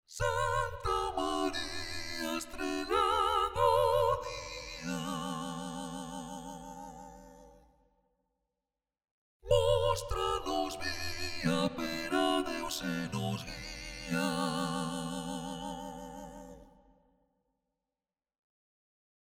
Frequenzschieber werden immer wieder gerne für Stimmverfremdungen eingesetzt, hier einige Beispiele: